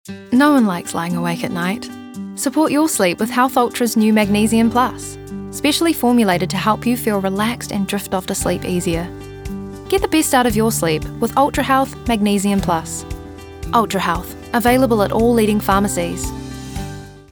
Her voice spans from warm and smooth to friendly and energetic, maintaining a sense of trustworthiness, sophistication, and eloquence that suits a variety of voice work.
warm/friendly